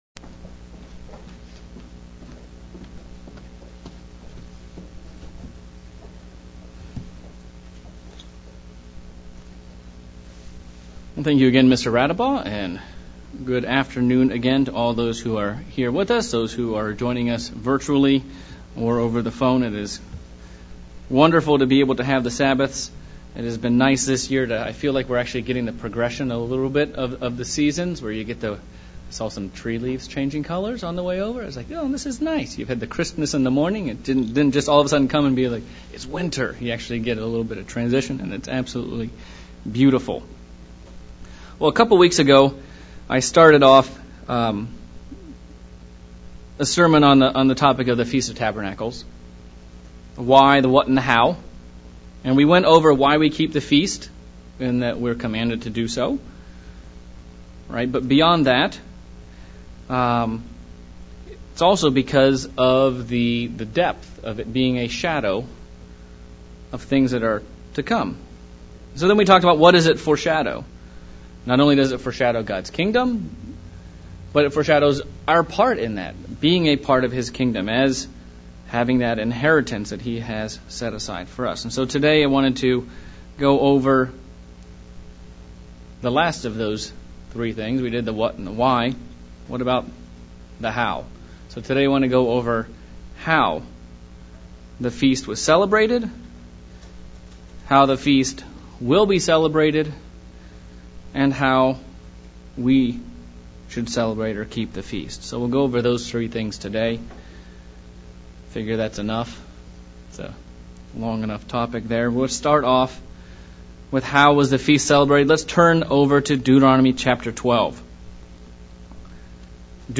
Sermon looking at the Feast of Tabernacles and how it was celebrated in OT / NT times.